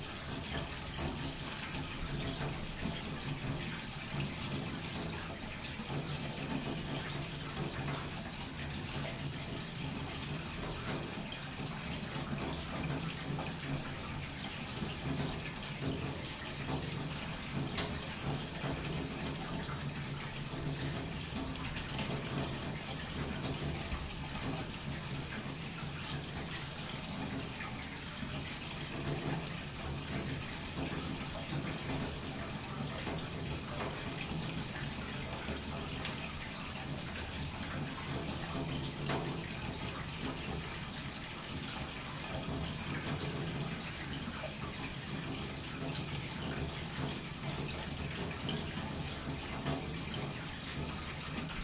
厨房水槽有流动的水
描述：在水槽下流动的水
标签： 水槽 移动 水龙头
声道立体声